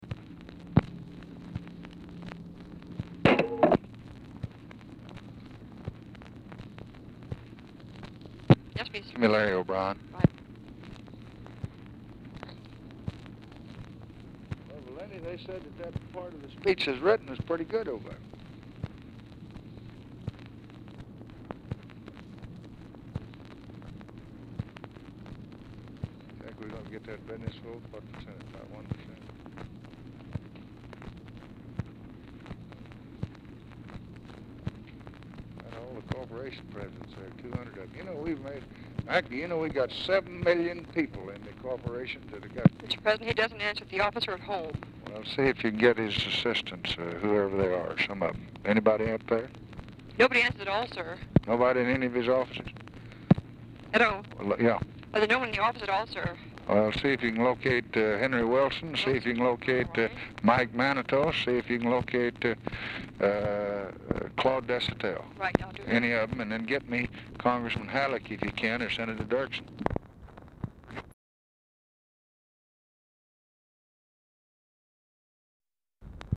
Telephone conversation
OFFICE CONVERSATION WHILE LBJ IS WAITING FOR CALLS TO BE PLACED
Format Dictation belt
Oval Office or unknown location